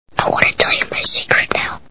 Sixth Sense Movie Sound Bites